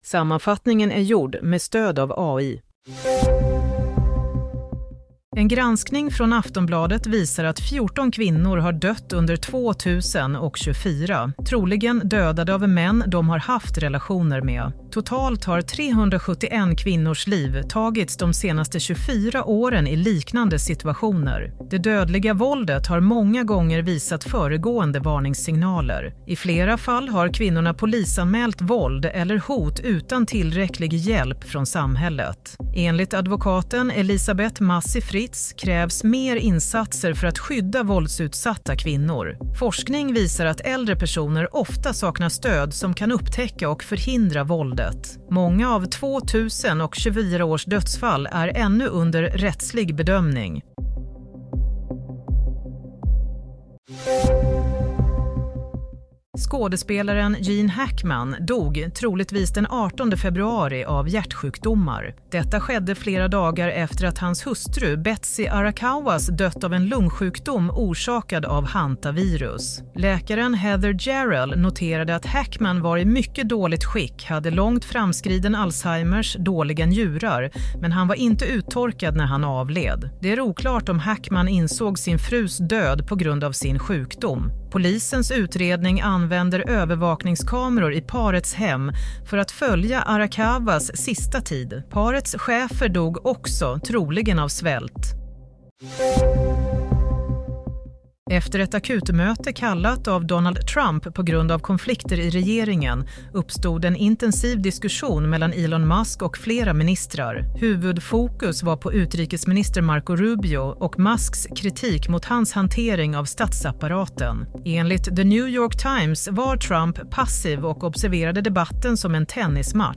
Play - Nyhetssammanfattning - 8 mars 07:00